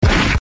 Tim Westwood Gunshot